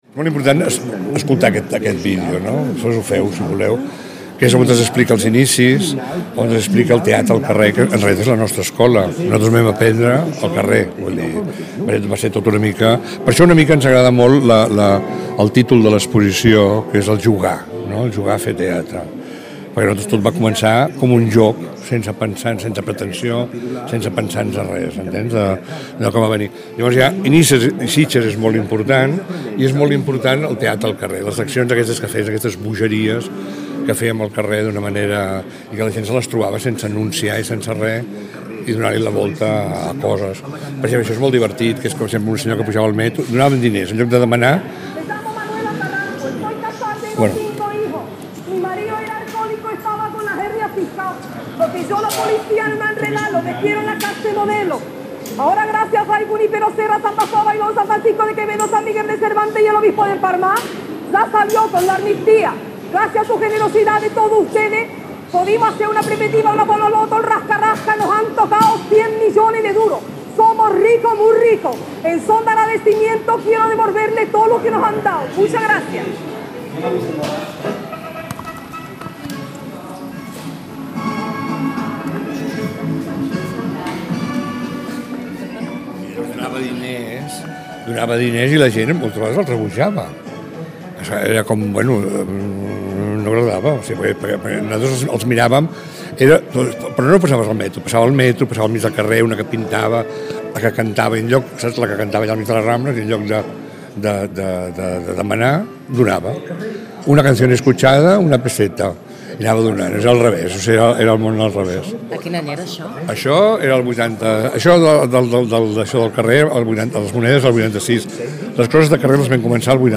Hem conversat amb el director de la companyia, Jordi Milán, alhora que ens feia una petita visita guiada per l’exposició que repassa la trajectòria de La Cubana.